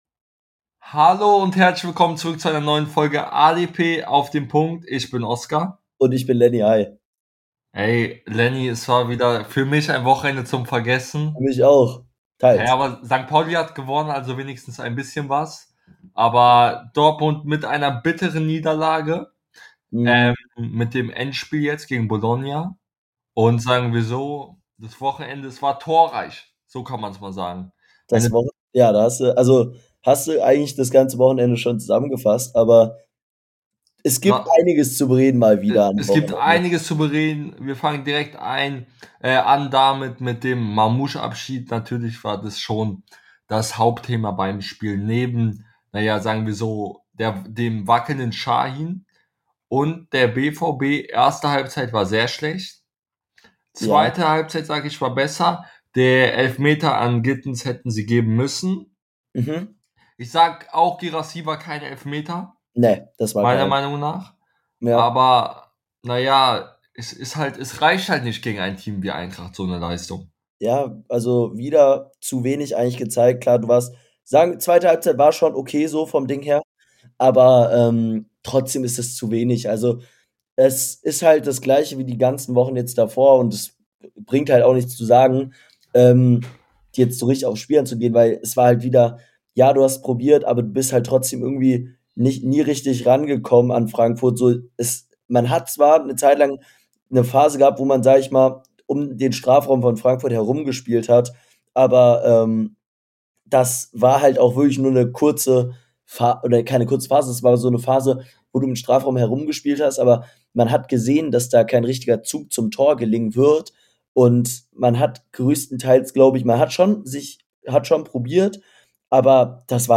In der heutigen Folge reden die beiden Hosts über das Bundesliga Wochenende , blicken auf die spannende 2 Liga , mit dem engen Aufstiegsrennen und vieles mehr